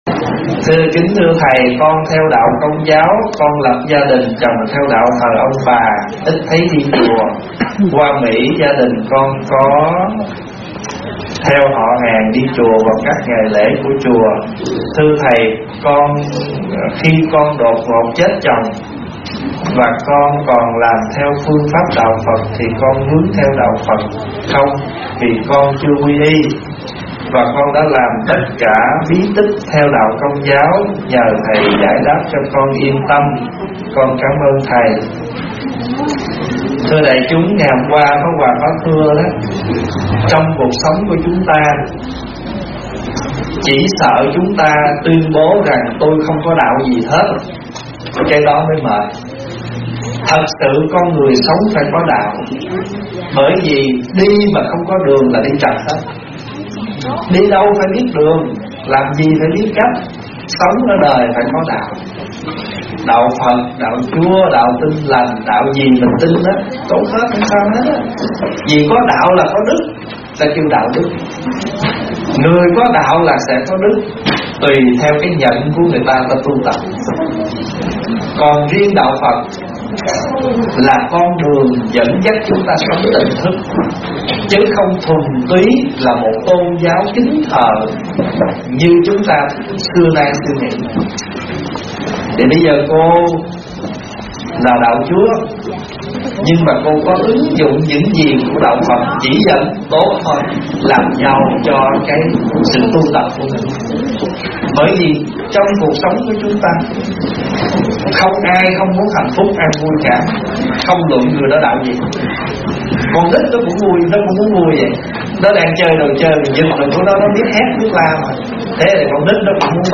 Nghe Mp3 thuyết pháp Sống Trên Đời Phải Có Đạo